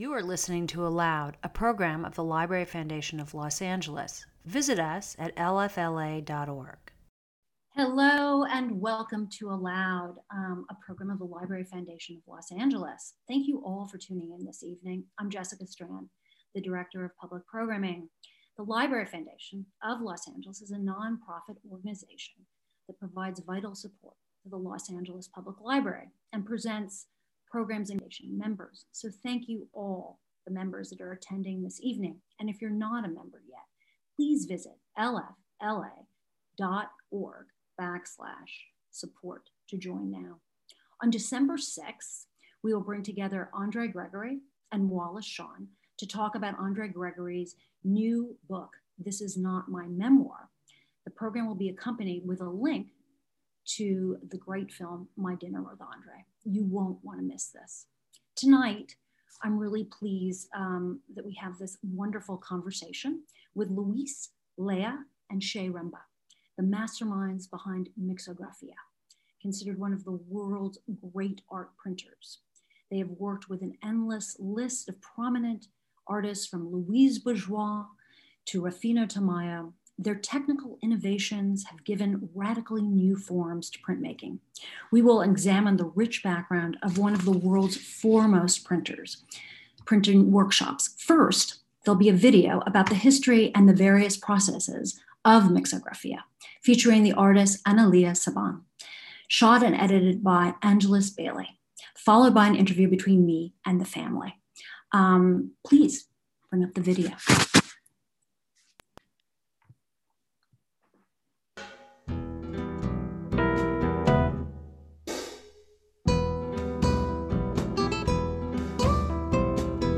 ALOUD is the Library Foundation of Los Angeles' award-winning literary series of live conversations, readings and performances at the historic Central Library and locations throughout Los Angeles.